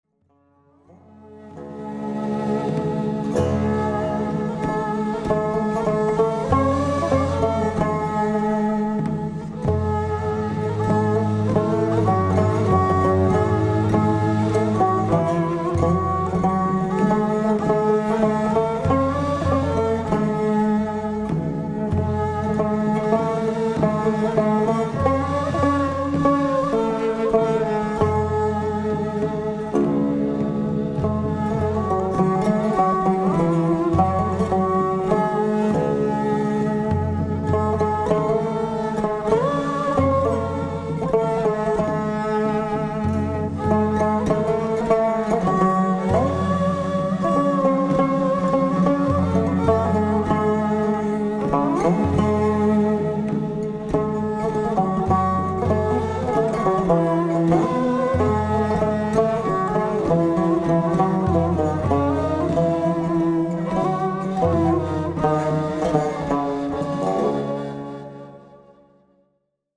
Genre: Turkish & Ottoman Classical.